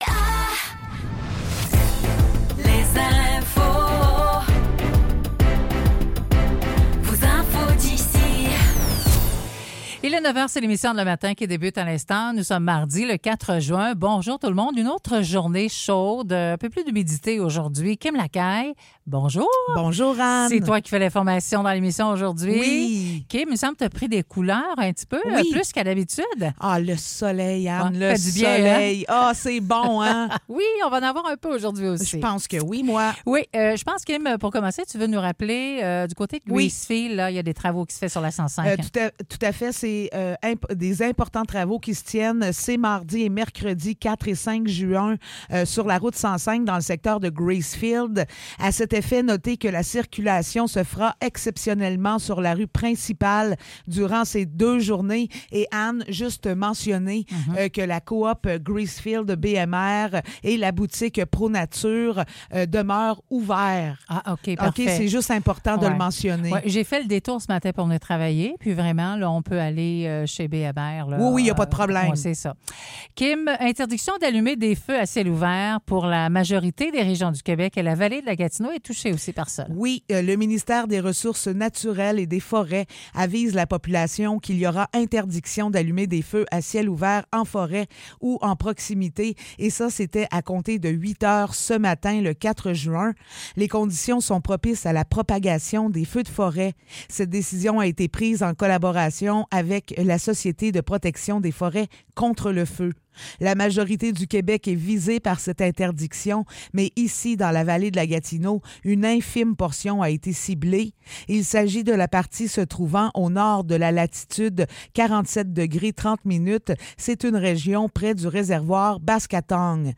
Nouvelles locales - 4 juin 2024 - 9 h